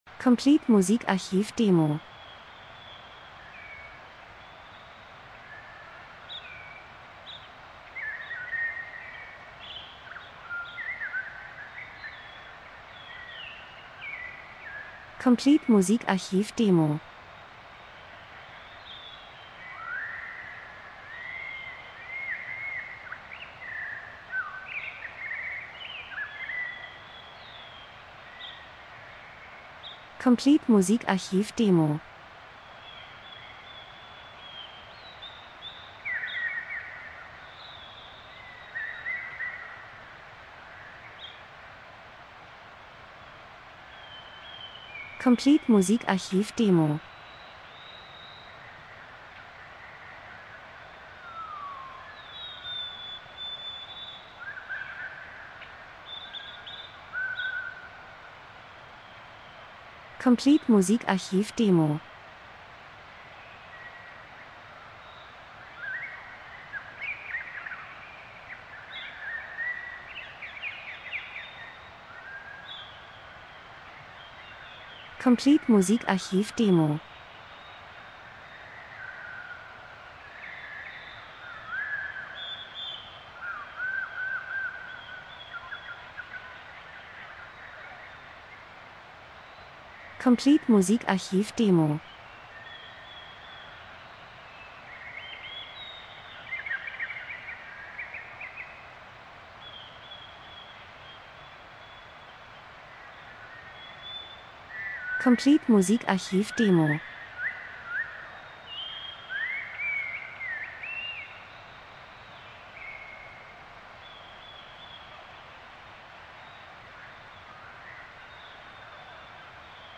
Frühling -Geräusche Soundeffekt Natur Vögel Wind Wiese 02:56